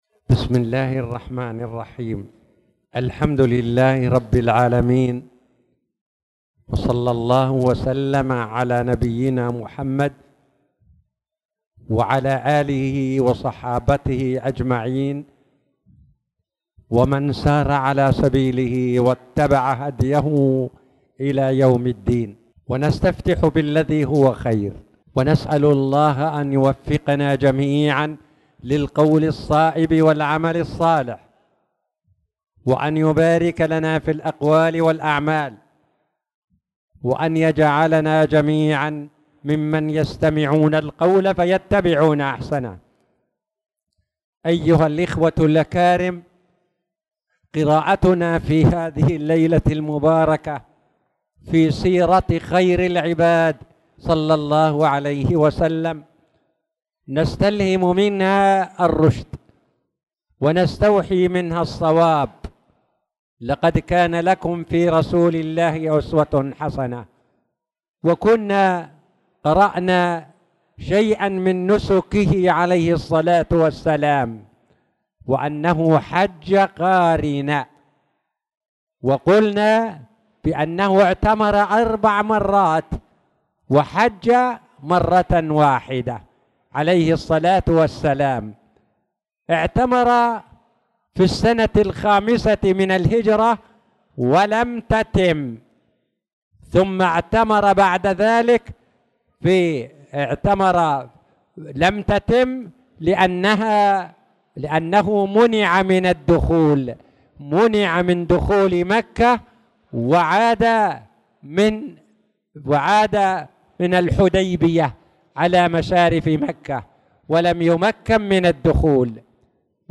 تاريخ النشر ١٤ ربيع الثاني ١٤٣٨ هـ المكان: المسجد الحرام الشيخ